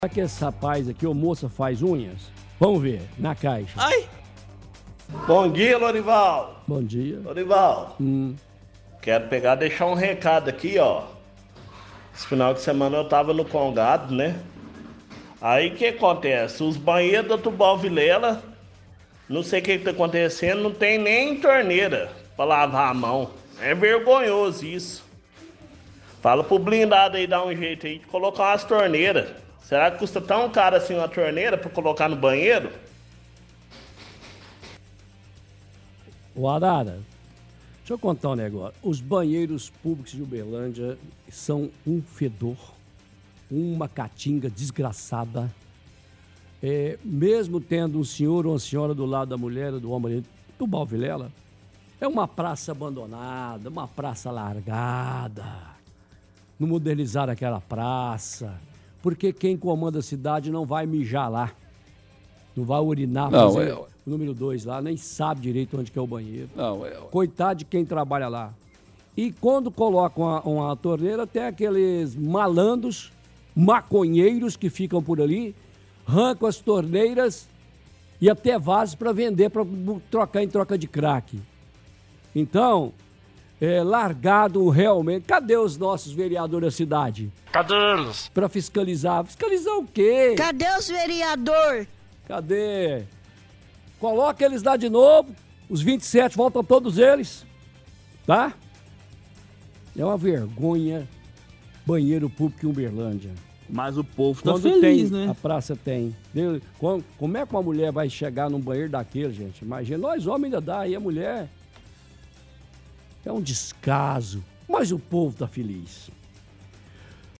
– Ouvinte reclama dos banheiros da praça Tubal Vilela, diz que estava na comemoração do Congado neste fim de semana e pede para o prefeito Odelmo resolver a situação.